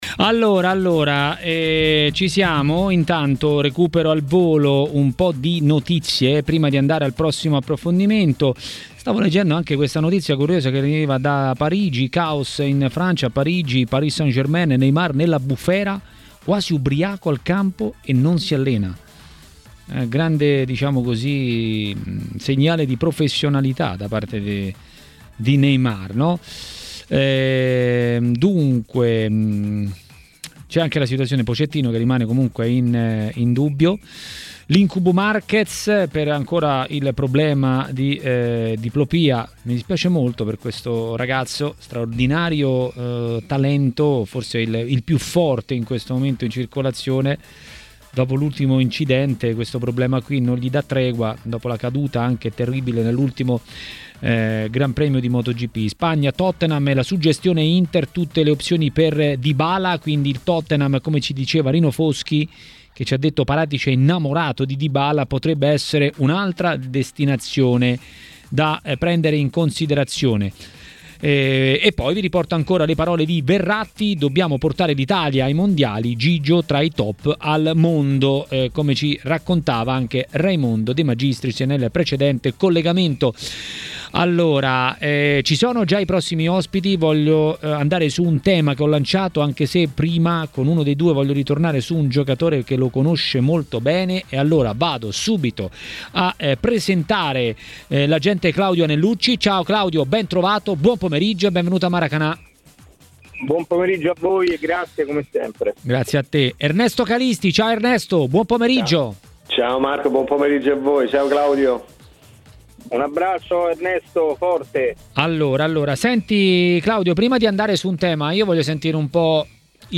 Maracanà, trasmissione di TMW Radio